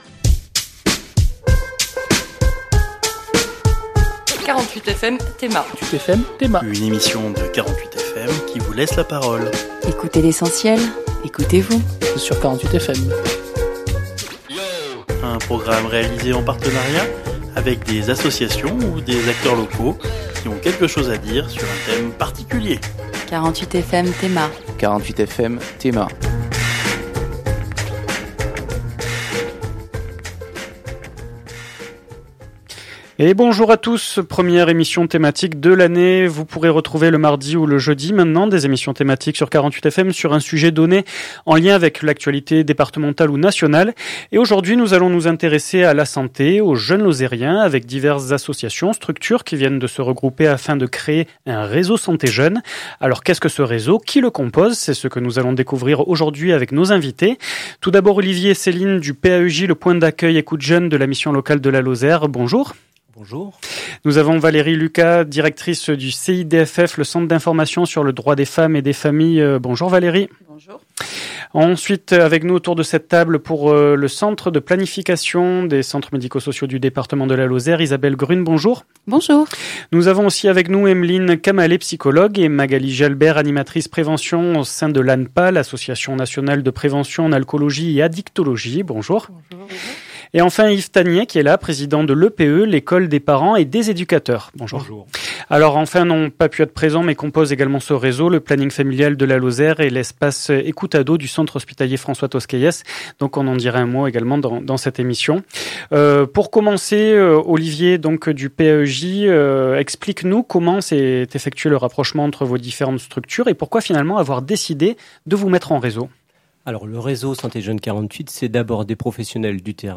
Le réseau santé jeunes 48 Emission en direct le jeudi 27 septembre à 12h30